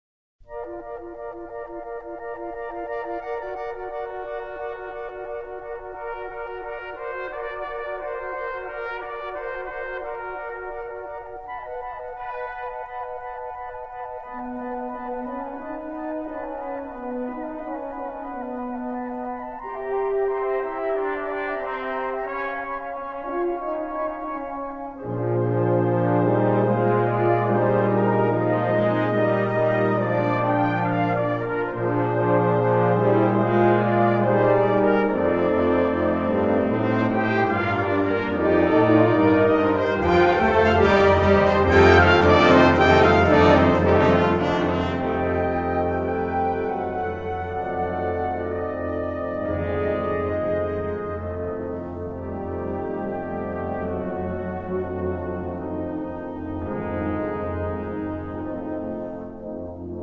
virtuoso music for clarinet and wind band